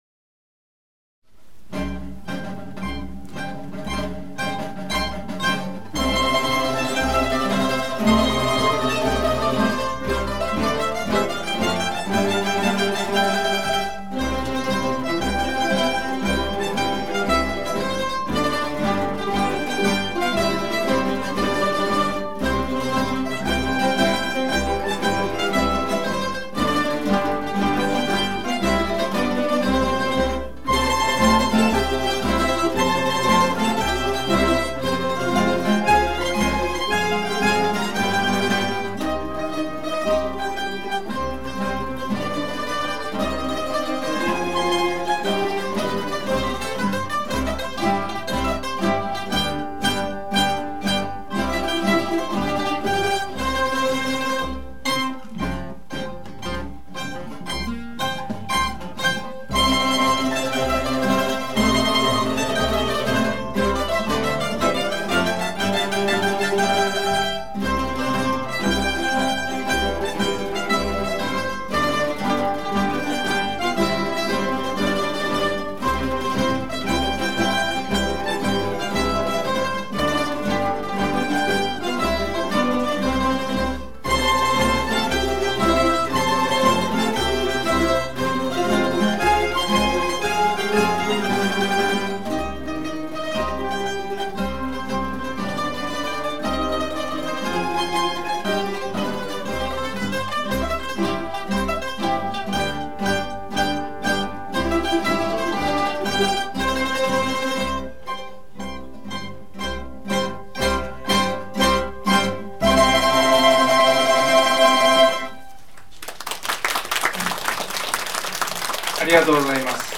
2019新磯公民館新春マンドリンコンサート
☆ 会　　場 新磯公民館　２F大会議室
客席はぎっしり満員